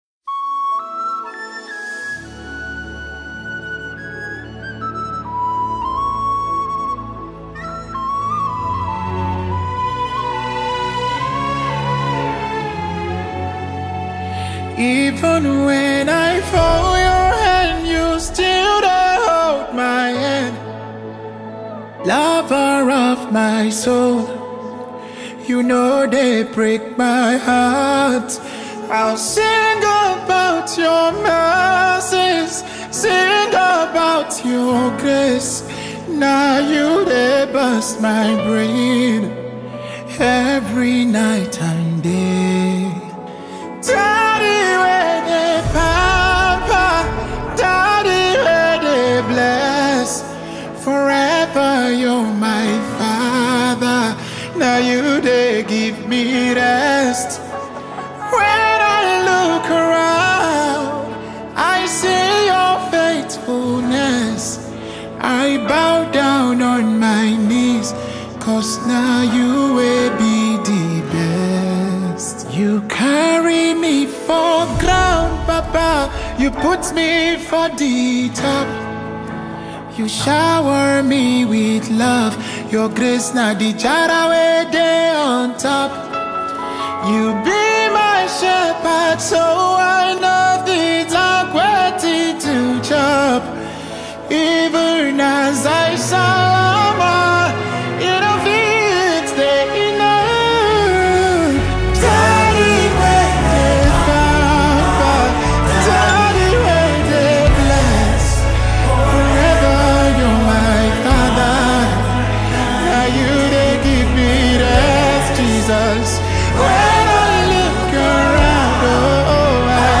Genre:Gospel